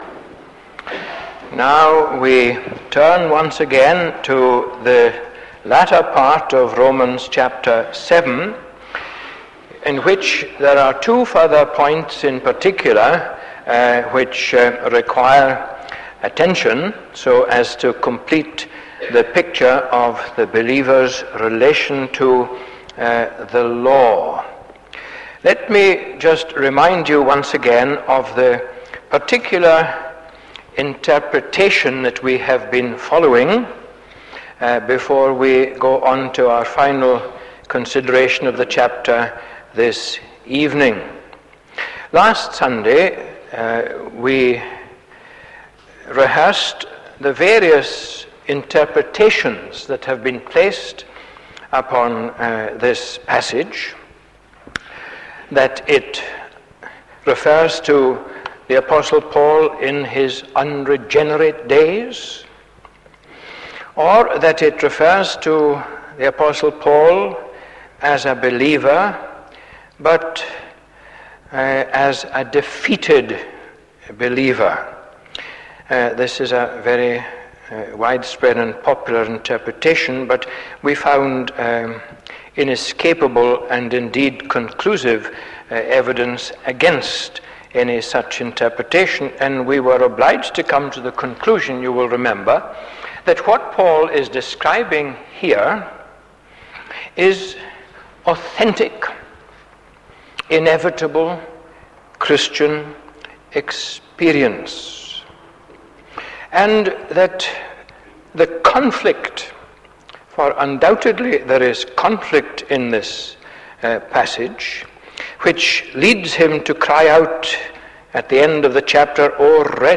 is a sermon I heard back in 1988.